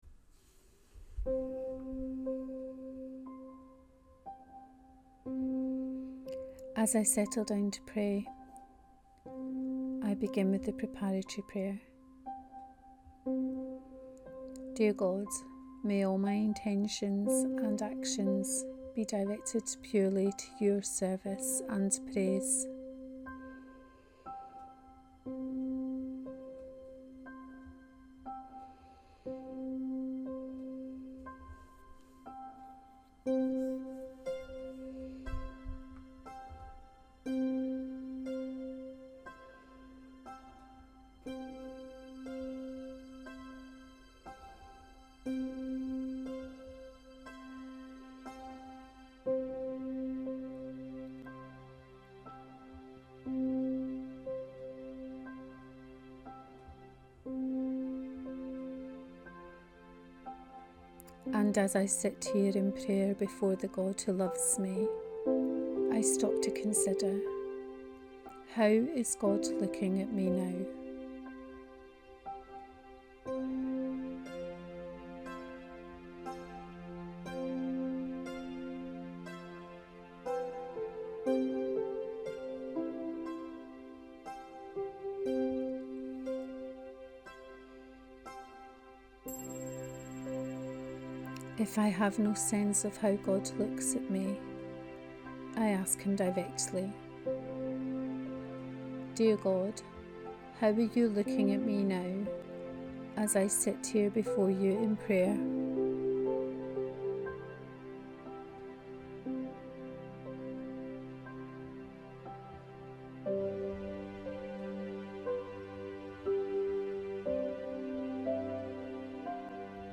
Imaginative contemplation guided prayer on the Sunday lectionary gospel for Palm Sunday cycle B. Mark 15: 16-20